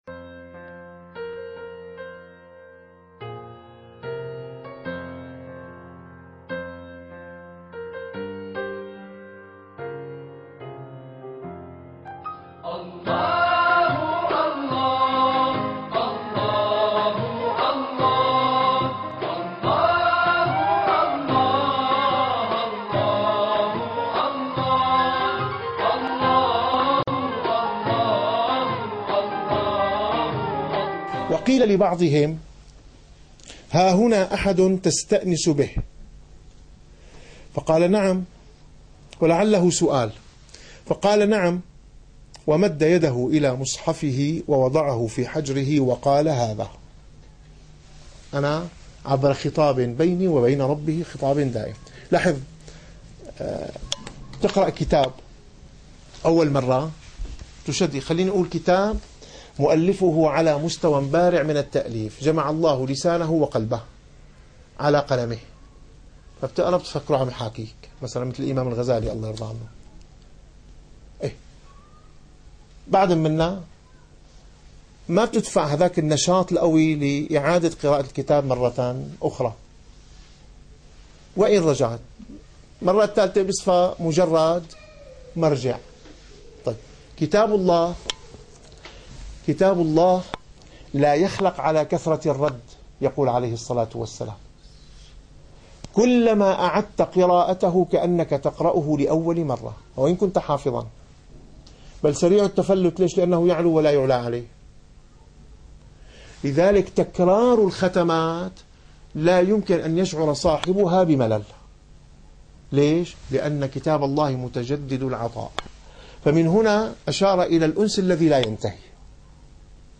- الدروس العلمية - الرسالة القشيرية - الرسالة القشيرية / الدرس الواحد والأربعون.